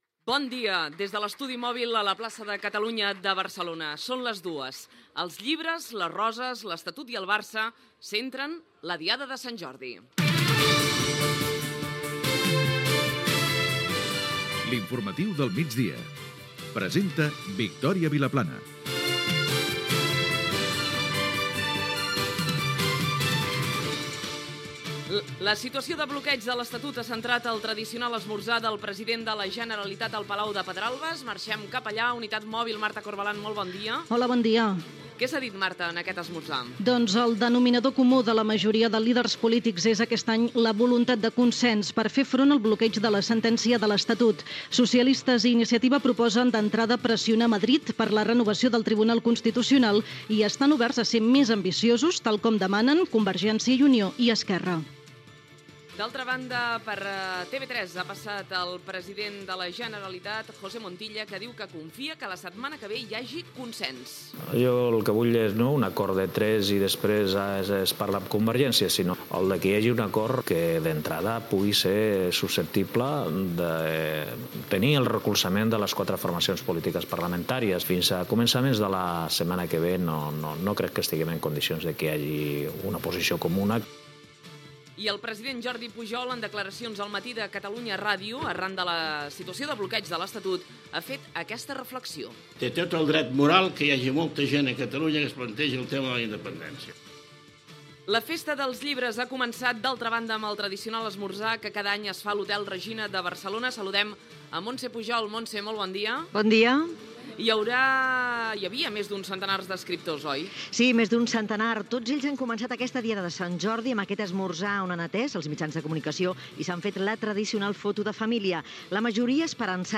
Espai fet des de l'estudi mòbil a la Plaça de Catalunya de Barcelona amb motiu de la Diada de Sant Jordi.
Informatiu